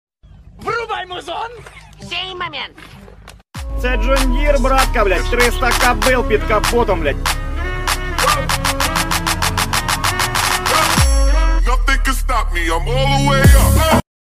John Deere 6250R Row Crop Tractor sound effects free download